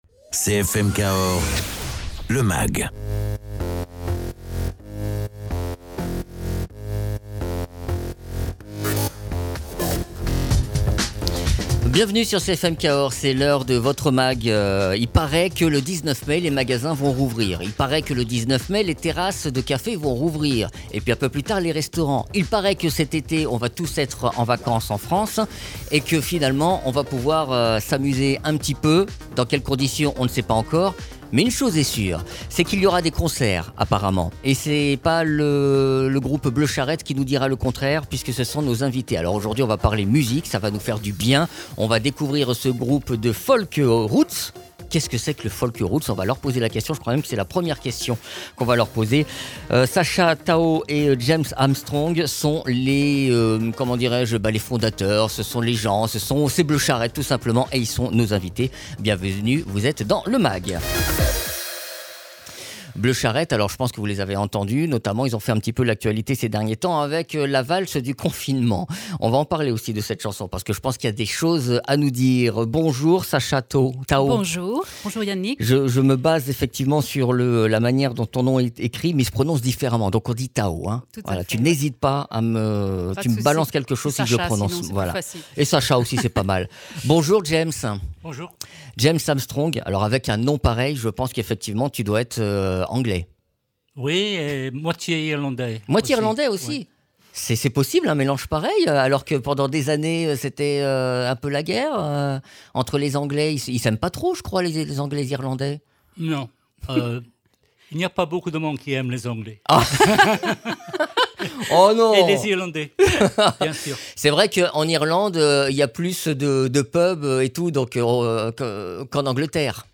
Le duo Bleu charrette est venu parler de leur travail et de leur dernier titre